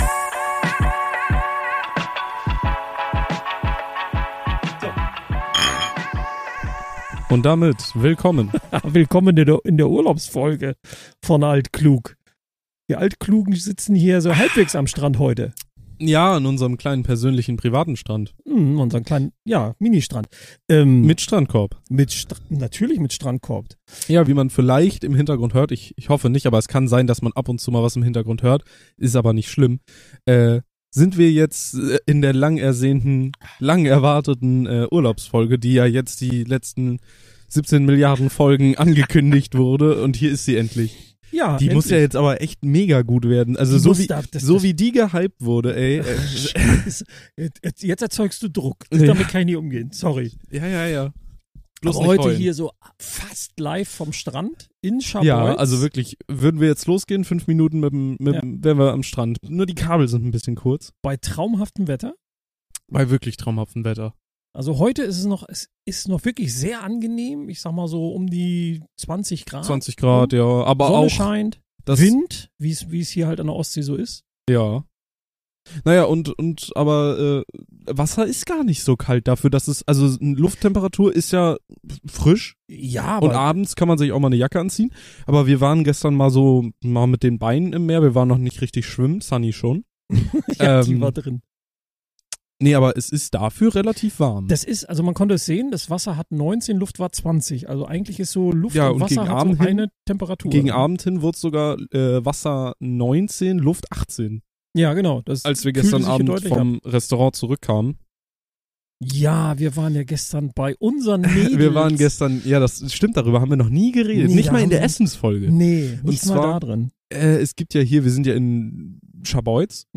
Heute senden wir endlich die ersehnte, erste Urlaubsfolge aus Scharbeutz. Wir schwelgen in Erinnerungen und stellen fest, dass Scharbeutz für uns einen ganz eigenen Geschmack hat.